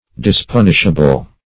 Search Result for " dispunishable" : The Collaborative International Dictionary of English v.0.48: Dispunishable \Dis*pun"ish*a*ble\, a. Without penal restraint; not punishable.
dispunishable.mp3